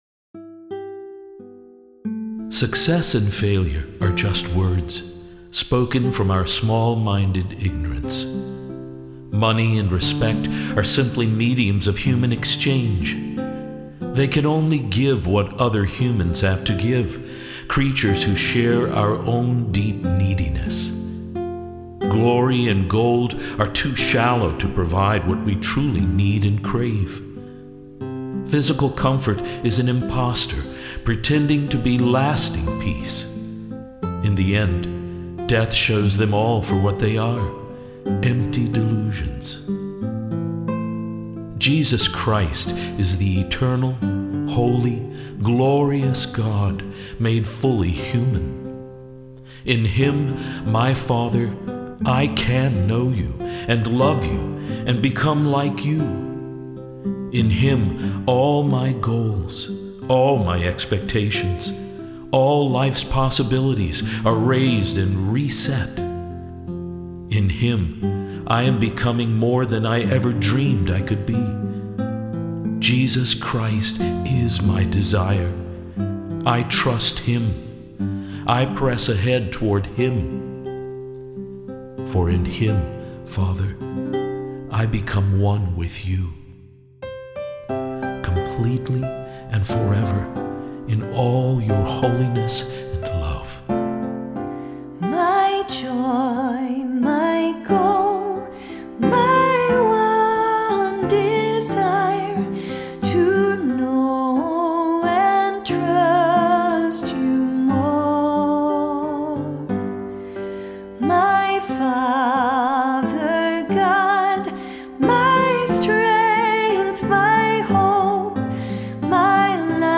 Each includes a devotional reading over instrumental music, leading into a hymn.